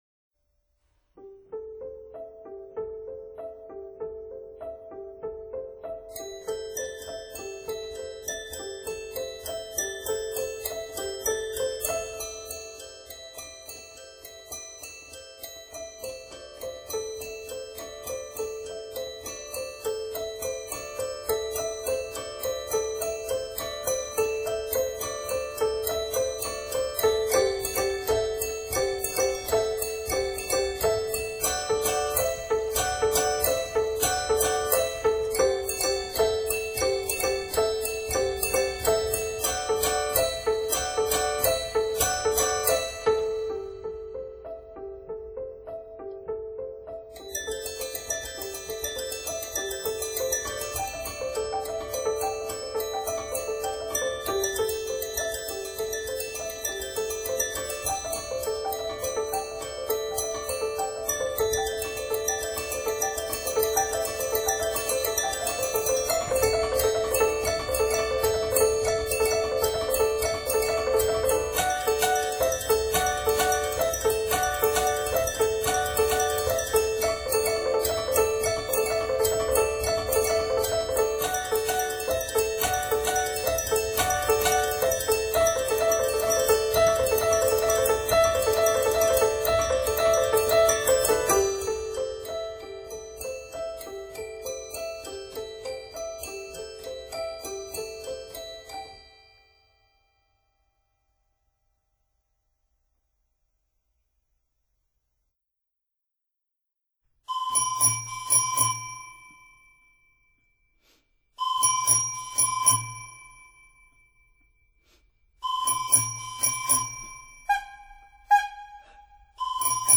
Classical/Avant Garde